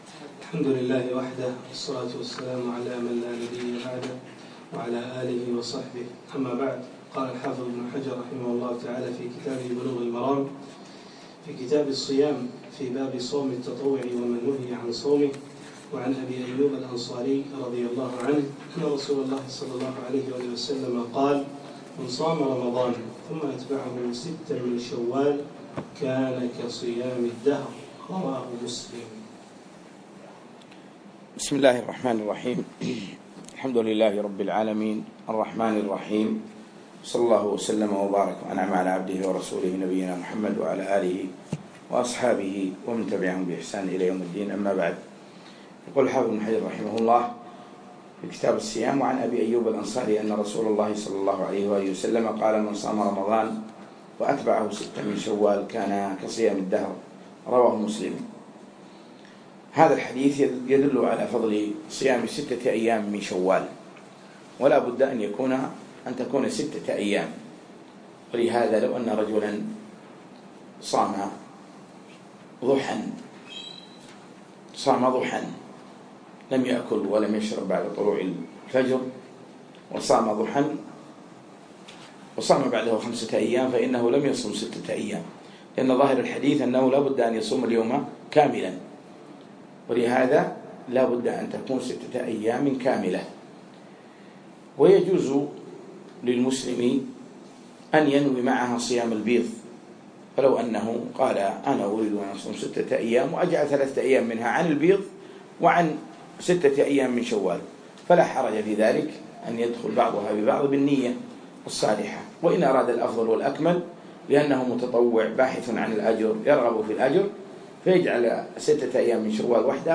يوم الجمعة 27 شعبان 1437هـ الموافق 3 6 2016م في مكتبة ابن حجر قطر
الدرس الرابع